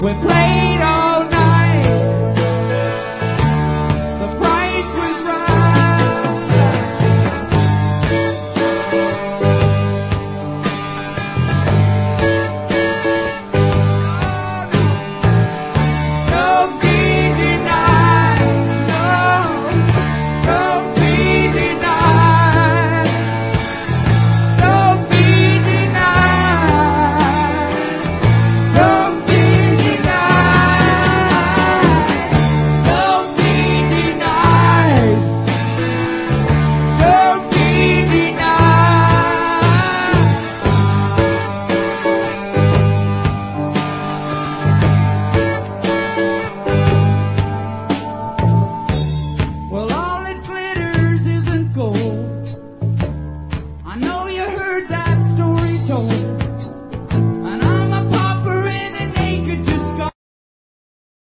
レアなジャズ・ファンク/レアグルーヴ盛り沢山の超即戦力コンピレーション！